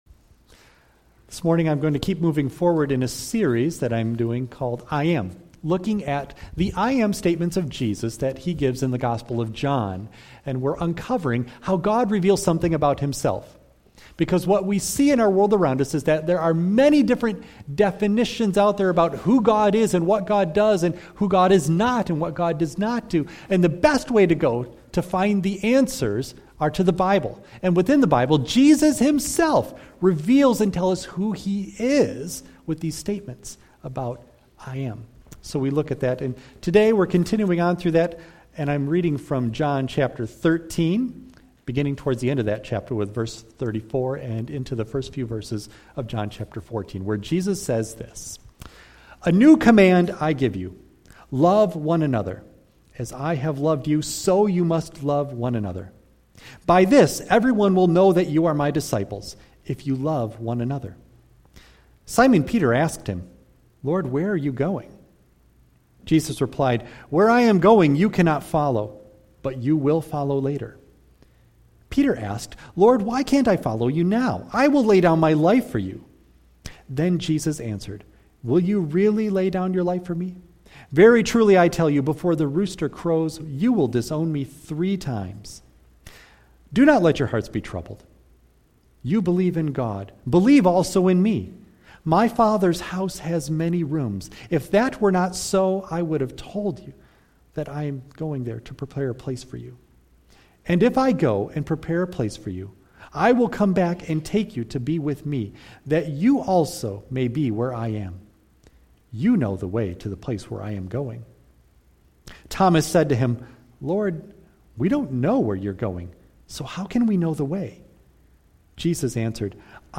John 13:34-14:7 Service Type: Sunday AM Bible Text